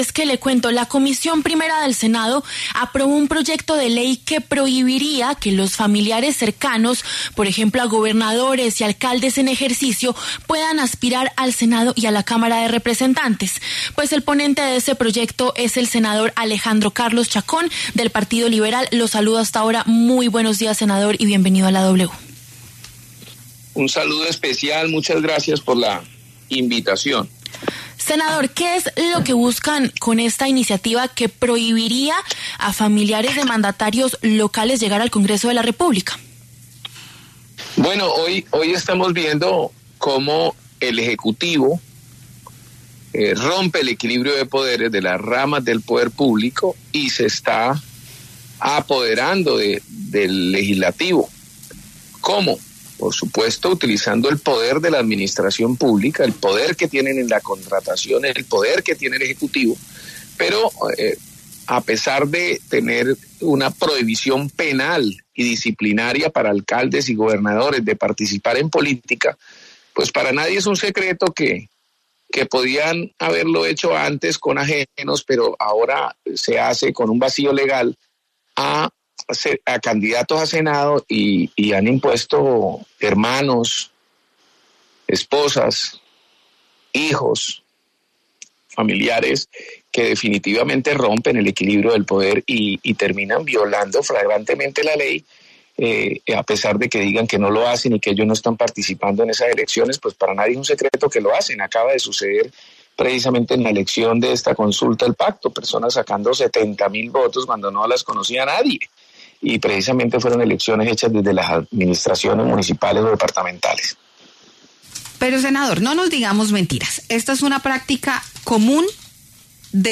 Debate: ¿es oportuno el proyecto que prohíbe que familiares de alcaldes o gobernadores aspiren al Congreso?